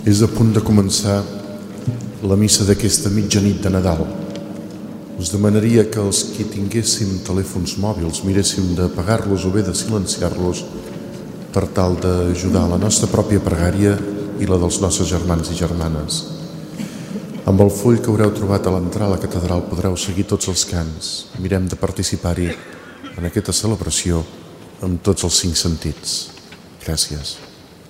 Avís previ a l'inici de la missa del gall, des de la catedral de Girona